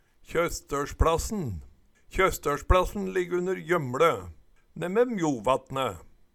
DIALEKTORD PÅ NORMERT NORSK KjøstøLspLassen Tjostolvsplassen u. Gjømle Tilleggsopplysningar Kjelde